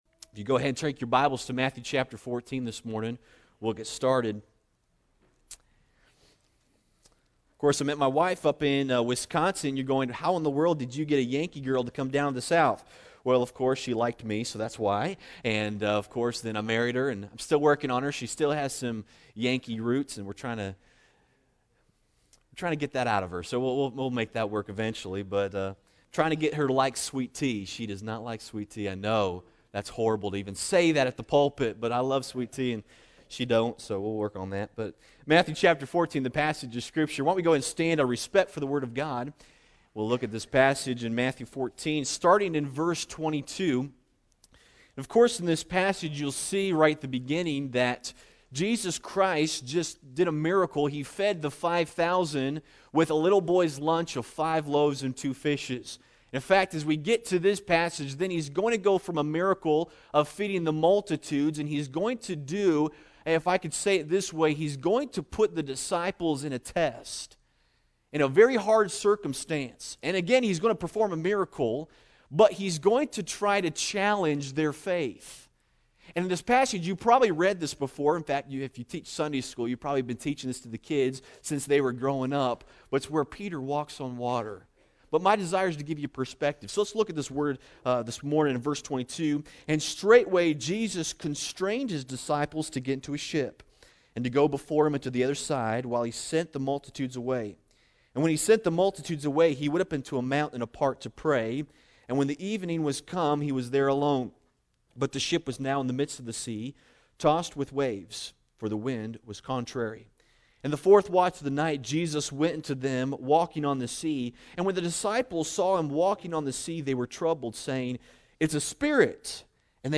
Challenging Your Faith - Pine Bluff Baptist